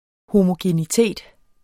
Udtale [ homogeniˈteˀd ]